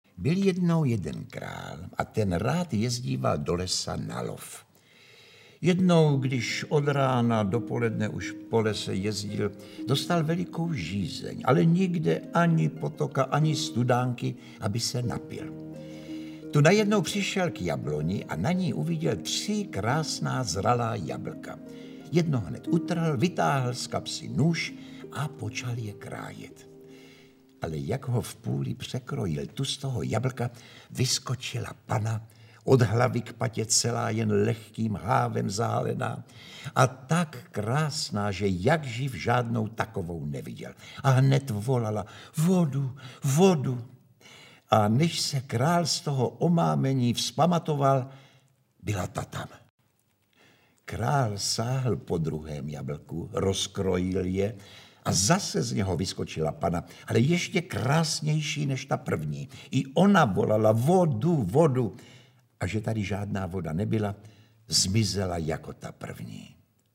Interpret: Josef Somr
Audiokniha Jabloňová panna, autor Karel Jaromír Erben, čte Josef Somr.